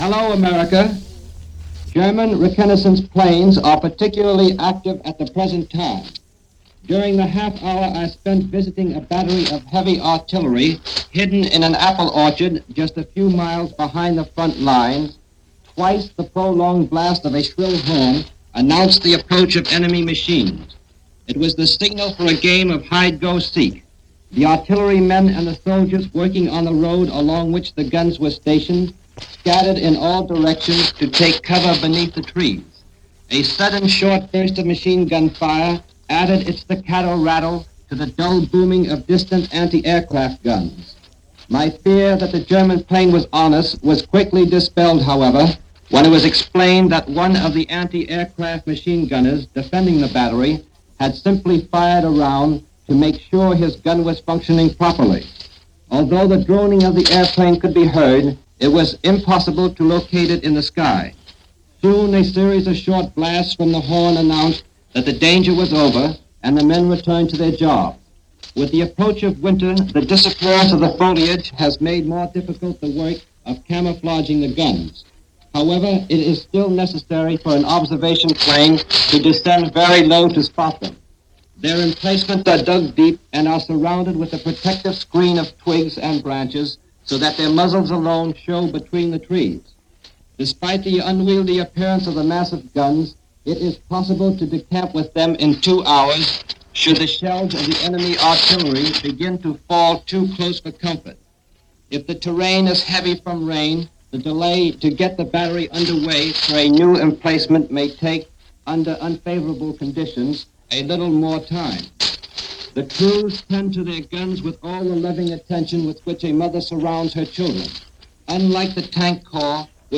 The French Army On Alert - Apprehension At The Front - November 23-24, 1939 - two reports from Mutual Correspondents via Shortwave.